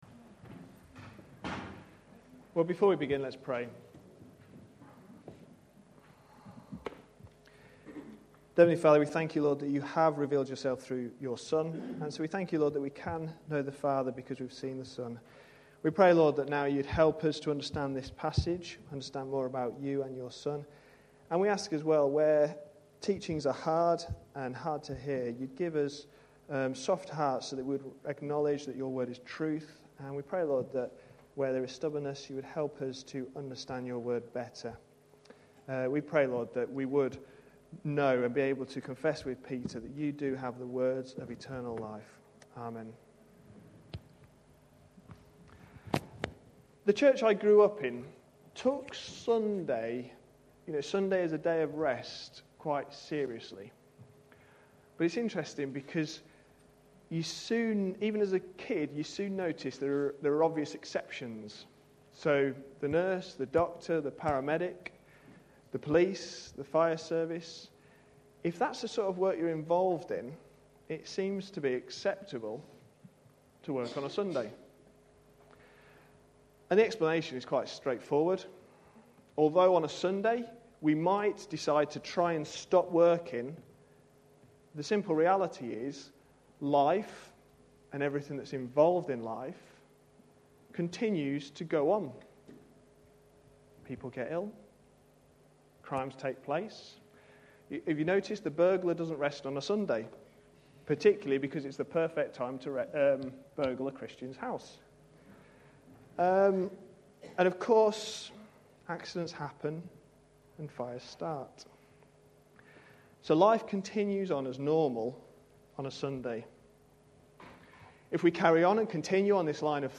A sermon preached on 17th April, 2011.